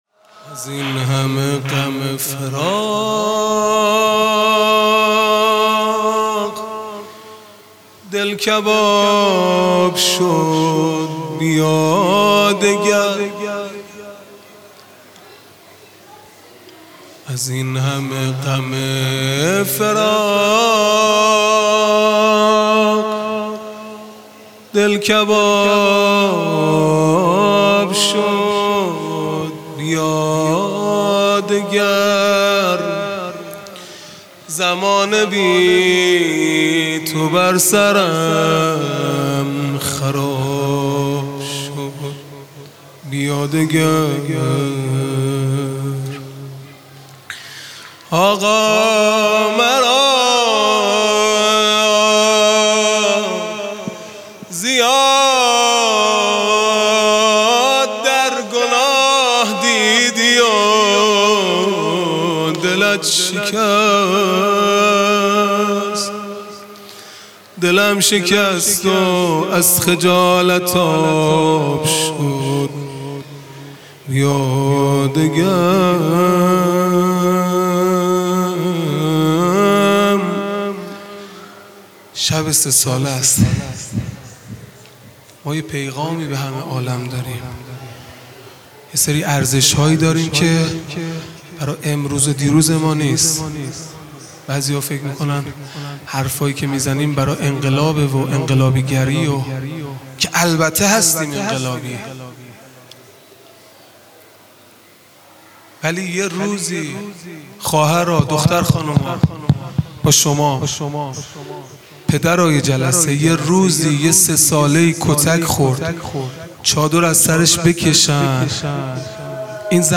مناجات پایانی | ازین همه غم فراق دل کباب شد بیا دگر | ۲۹ تیر ۱۴۰۲
محرم الحرام ۱۴۴5 | شب سوم | پنجشنبه 29 تیر ماه ۱۴۰2